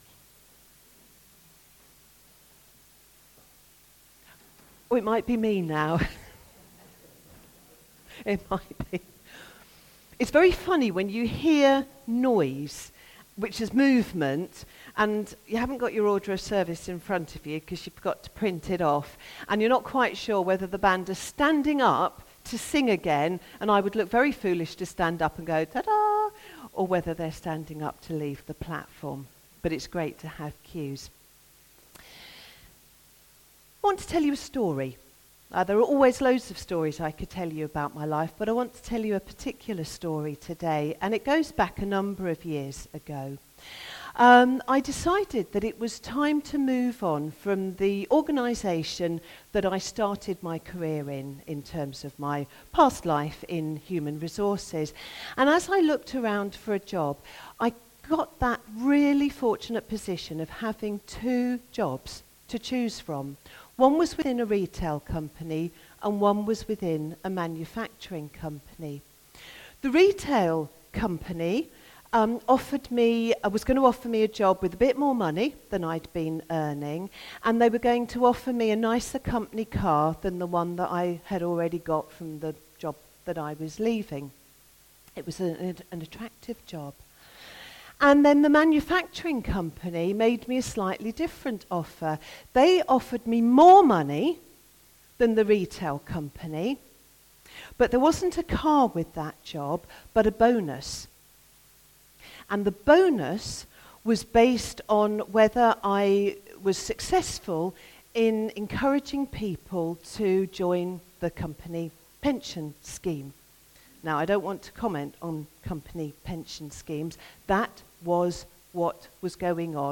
A message from the series "Jonah: Runaway Prophet."